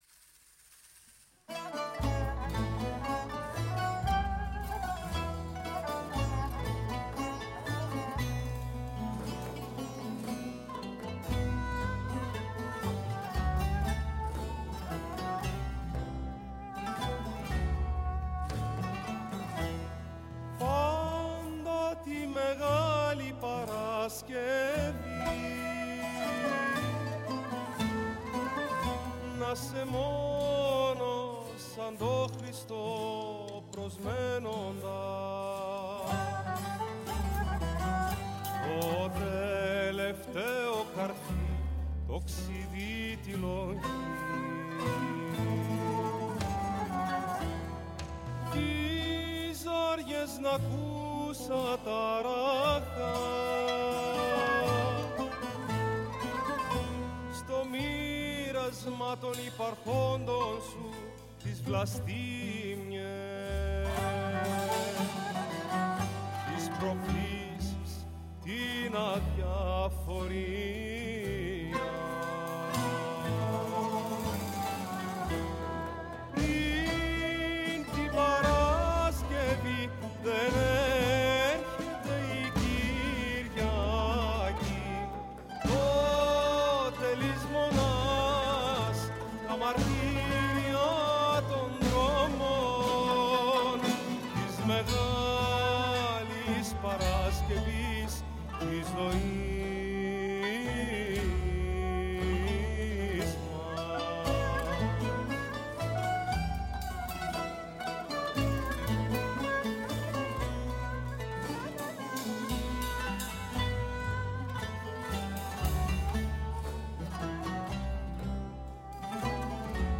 Στην έκτακτη ωριαία εκπομπή, που μεταδόθηκε το απόγευμα της Μεγάλης Παρασκευής, 10 Απριλίου 2026, προσαρμοστήκαμε στο κλίμα της ημέρας. Αρχικά, ταξιδέψαμε μέχρι την Κέρκυρα για να μάθουμε πως εξελίσσεται ο εορτασμός της μεγάλης Εβδομάδας στο νησί που αποτελεί έναν από τους πιο δημοφιλείς προορισμούς για αυτές τις ημέρες, εξαιτίας των ιδιαίτερων εθίμων και της κατανυκτικής ατμόσφαιρας που προσφέρει στους επισκέπτες. μας μίλησε ο κ. Νίκος Βραδής, Αντιδήμαρχος Πολιτισμού Δ. Κεντρικής Κέρκυρας και Διαποντίων Νήσων.